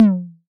Index of /musicradar/retro-drum-machine-samples/Drums Hits/Tape Path B
RDM_TapeB_SY1-Tom01.wav